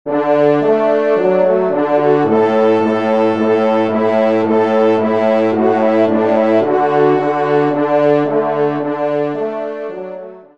Genre : Divertissement pour Trompes ou Cors
Pupitre 3° Trompe